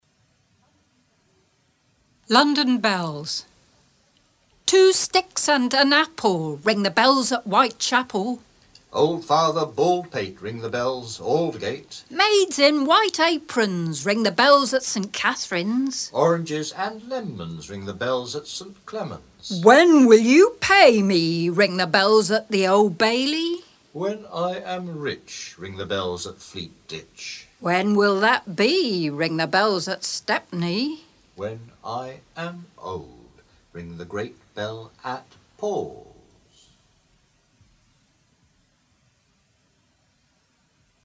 Recordings from a selection of 72 Poems on the Underground originally recorded on tape and published as a Cassell Audiobook in 1994